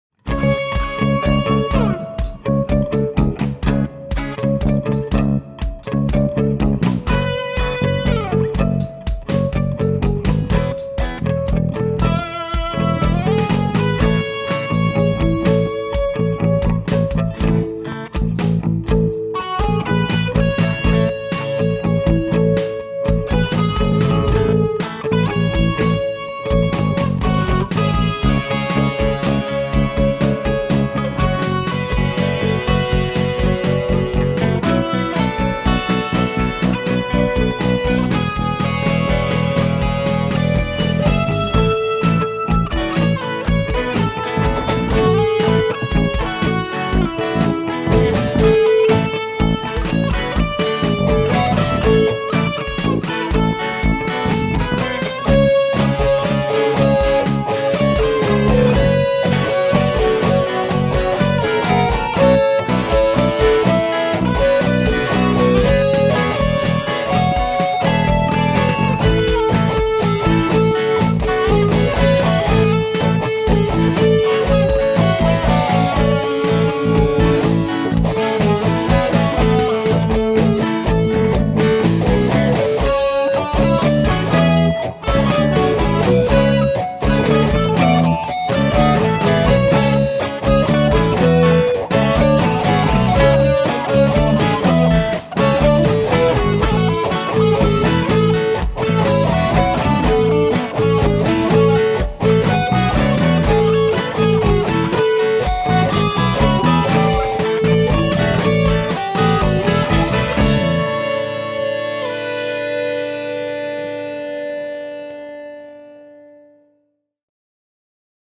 March Fourth — guitar harmonics riff